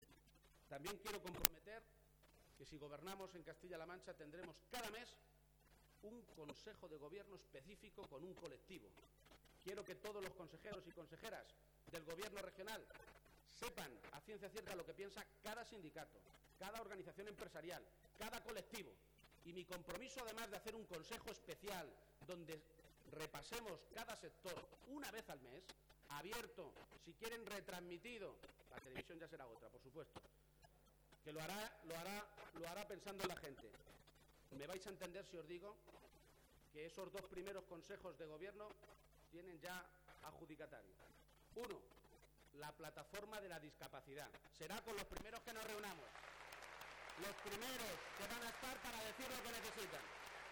García-Page hacía esta mañana en el Teatro Auditorio de Cuenca la presentación de su candidatura.